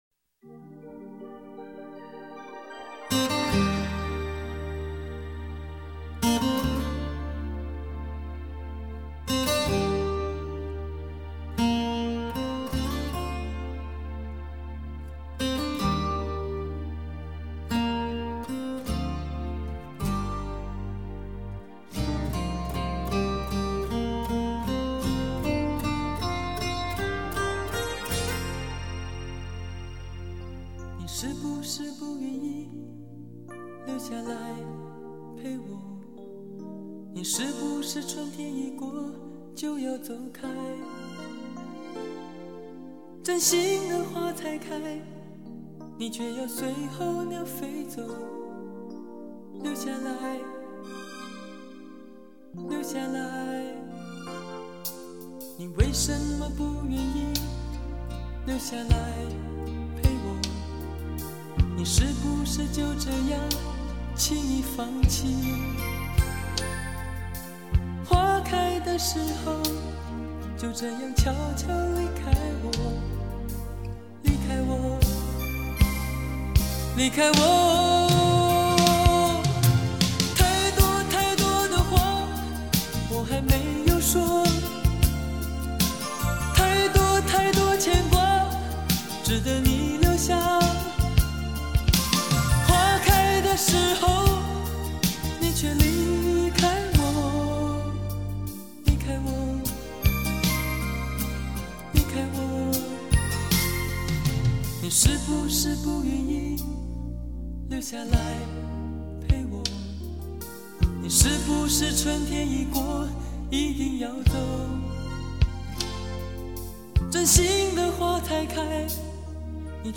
编曲和演唱都比1985年